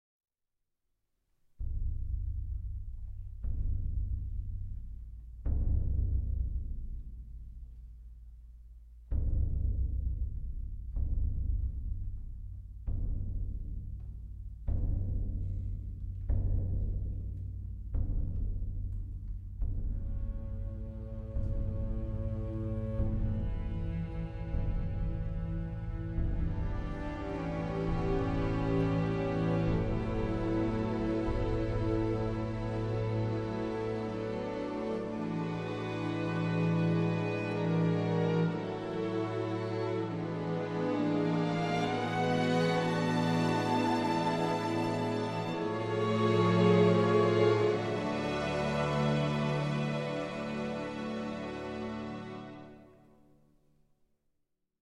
World Premiere Recording of the Complete Film Score